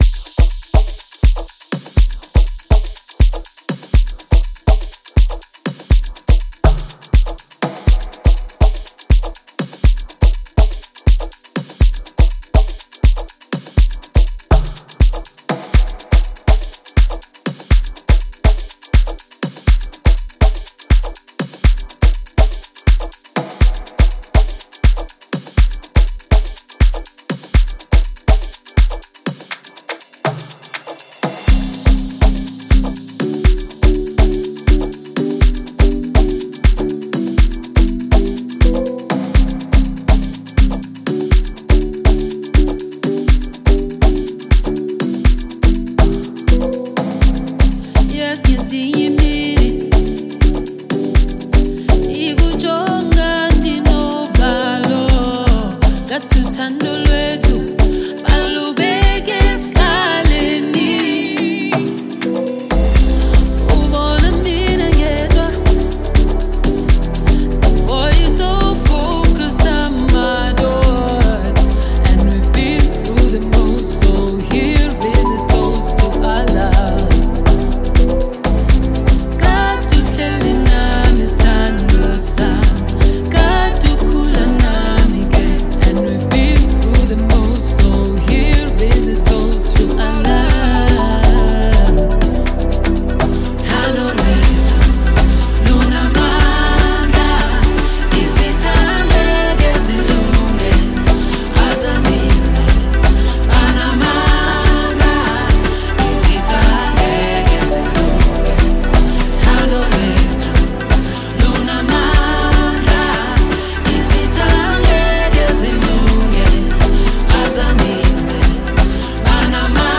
The song is a melodious one
• Genre: Afro-pop